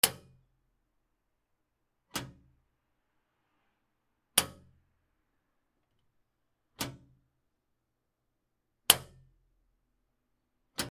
金属のスイッチ
/ M｜他分類 / L01 ｜小道具 / 金属